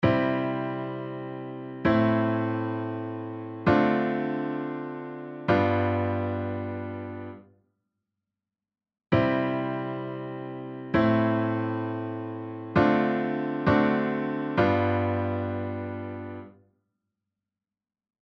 前半がC⇒Am⇒Dm7⇒V7、後半がC⇒Am⇒|Dm7 D7|⇒V7です。